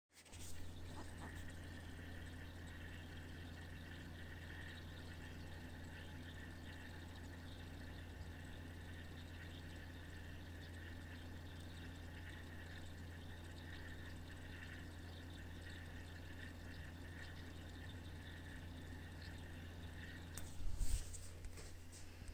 Bruit écoulement clim Daikin suite à rallonge
Sauf que depuis, ma clim fait un bruit de sifflement/écoulement de liquide dans tous les tuyaux (qui sont dans des goulottes) et surtout qui sort par la ventilation des UI et empêche de dormir.
Ci-joint le bruit anormal... !
J'entends un bruit d'écoulement de liquide plus aigu en arrière plan d'un ronronnement (ventilateur), c'est ça ?
Exactement, difficile de masquer le bruit du ventilateur à l'enregistrement...
C'est ce bruit d'écoulement qui est tout nouveau et extrêmement gênant j'ai l'impression qu'il y a un ruisseau dans ma maison...